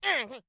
Cat Pain Sound Effect
Download a high-quality cat pain sound effect.
cat-pain-3.mp3